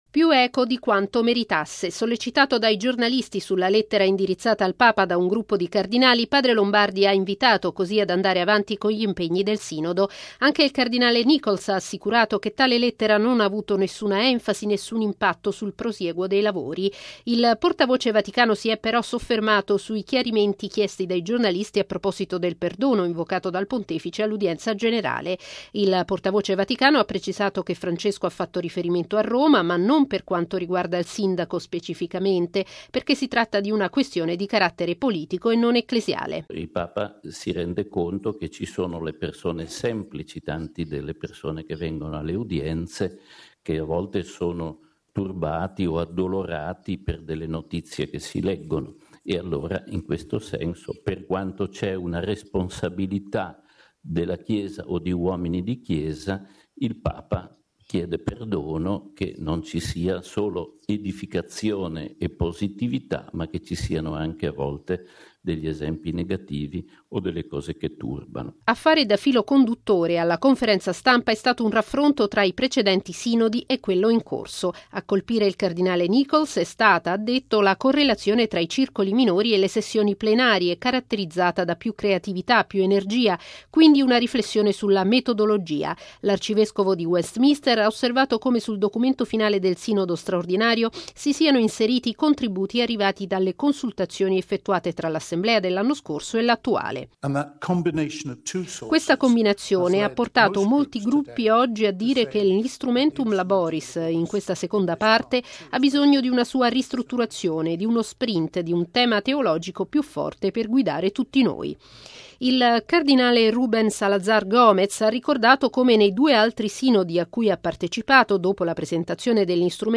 Al consueto briefing nella Sala Stampa della Santa Sede sui lavori della XIV Assemblea ordinaria del Sinodo dei Vescovi dedicata alla famiglia, hanno preso la parola anche il cardinale Rúben Salazar Gómez, presidente del Consiglio episcopale latinoamericano, il Celam, e il cardinale Philippe Ouédraogo, arcivescovo di Ouagadougou nel Burkina Faso.